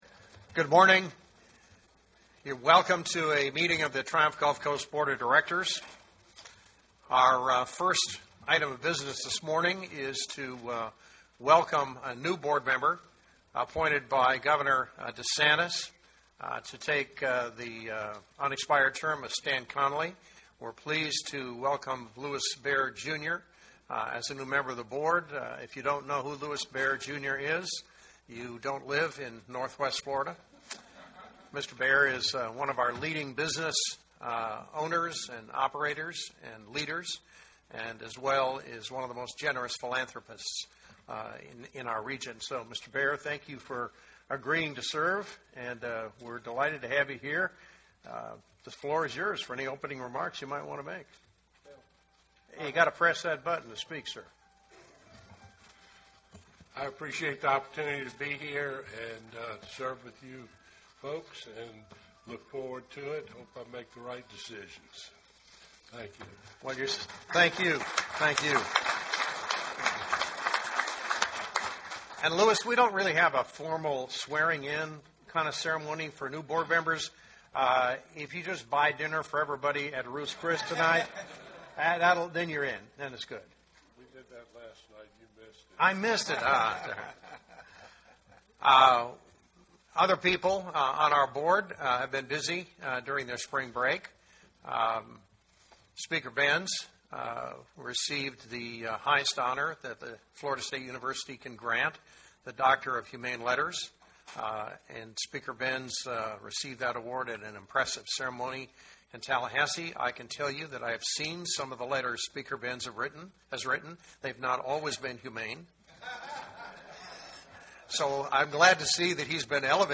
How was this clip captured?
The Board of Triumph Gulf Coast, Inc., met at 9:30 a.m., CT on Monday, April 29, 2019 at the Okaloosa County Commission Chambers, Shalimar Annex, 1250 North Eglin Parkway, Shalimar, Florida 32579.